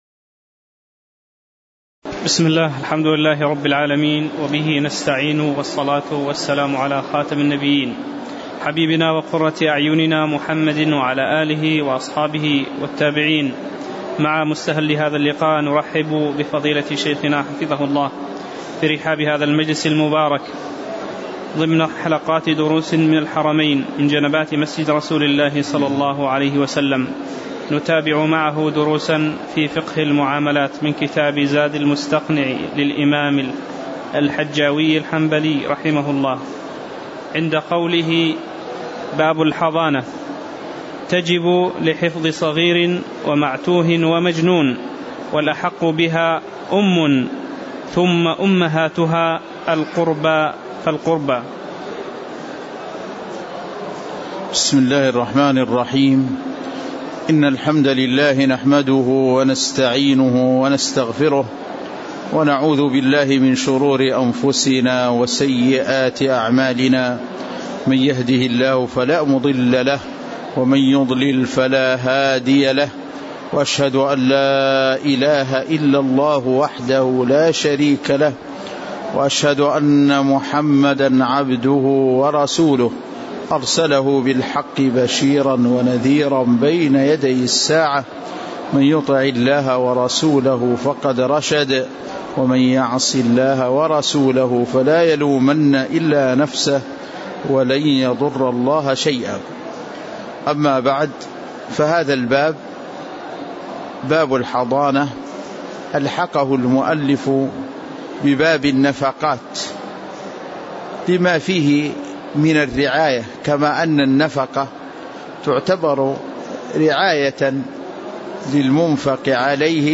تاريخ النشر ٢٧ صفر ١٤٣٨ هـ المكان: المسجد النبوي الشيخ